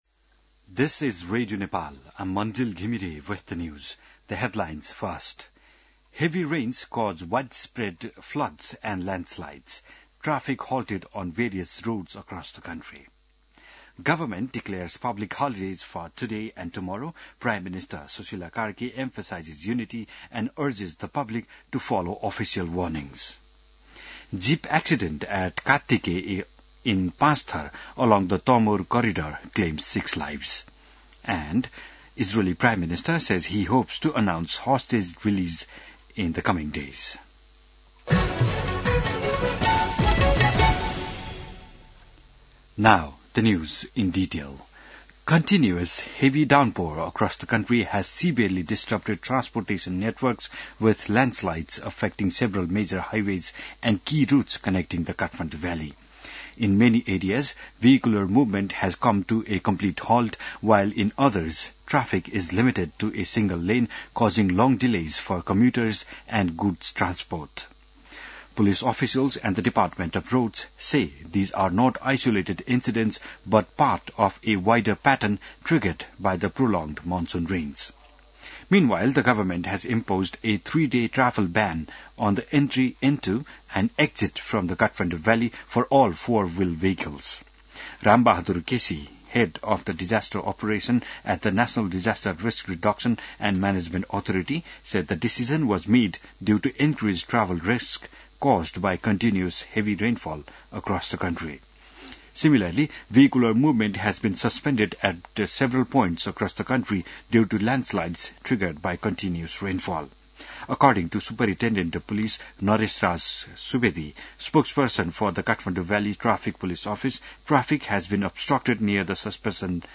बिहान ८ बजेको अङ्ग्रेजी समाचार : २७ वैशाख , २०८२